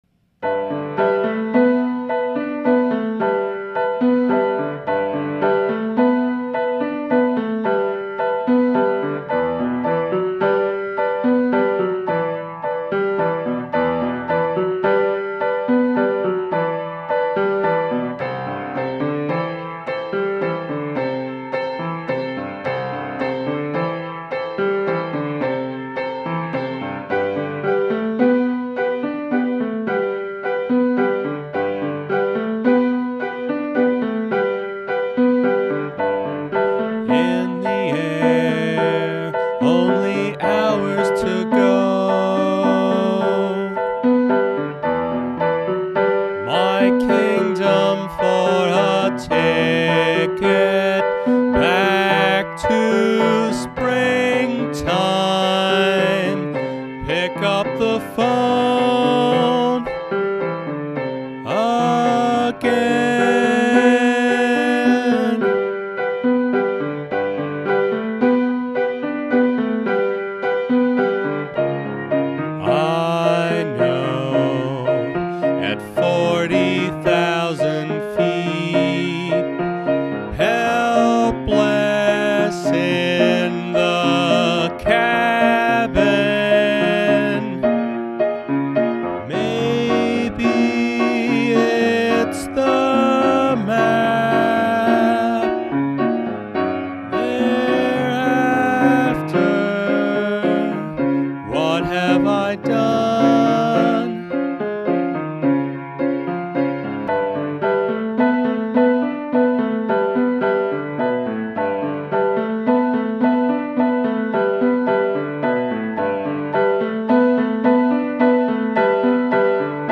rock opera
piano and voice